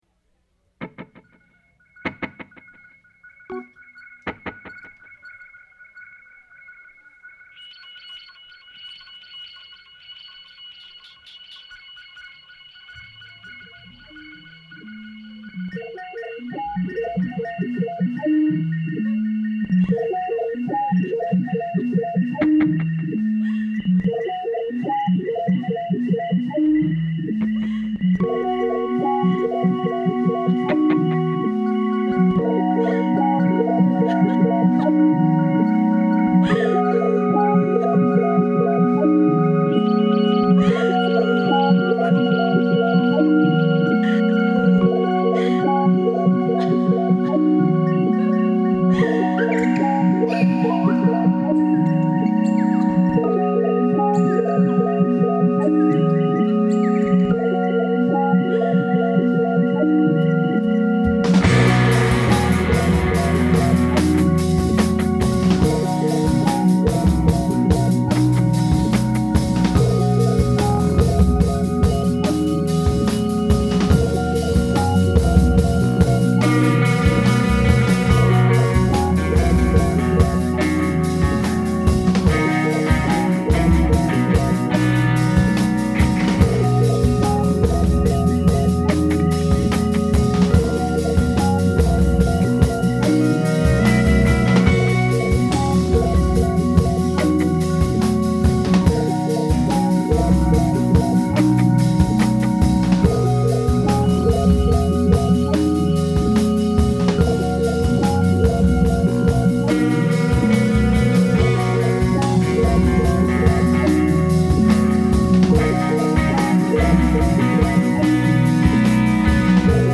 The guitar is played with a little bit of delay.